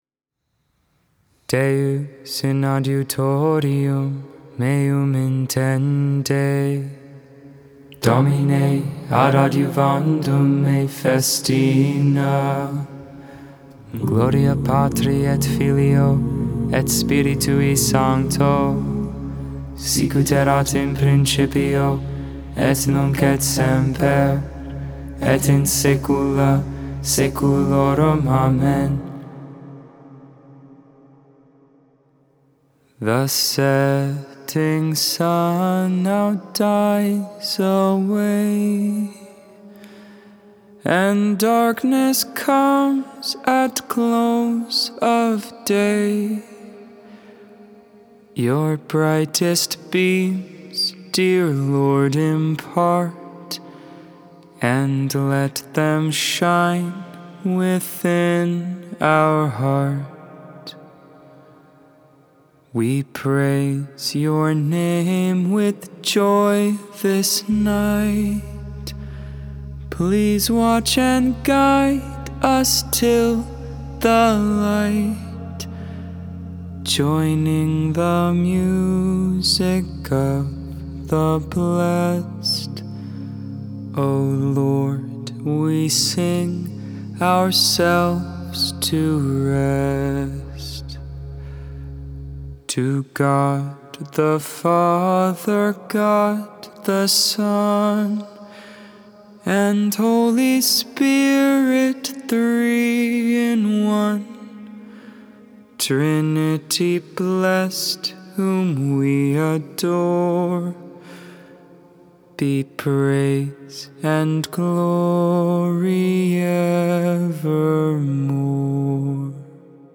3.8.22 Vespers, Tuesday Evening Prayer